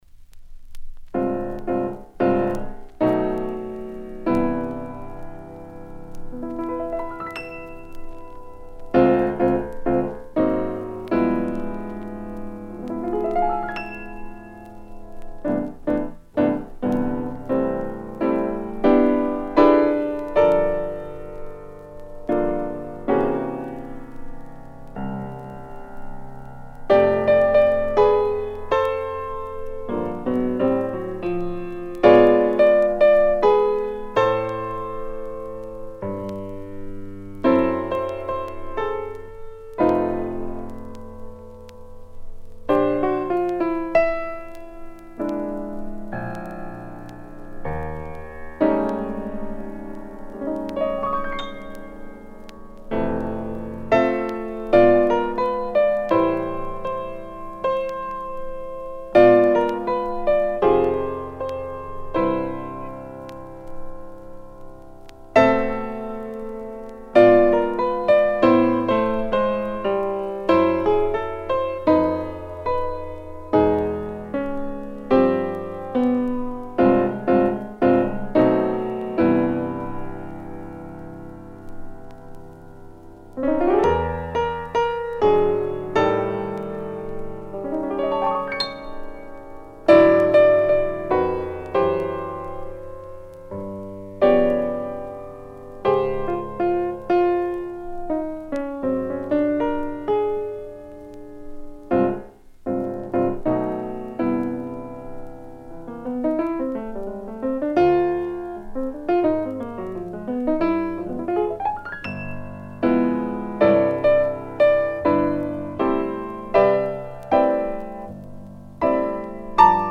Original mono pressing
at Reeves Sound Studios, NYC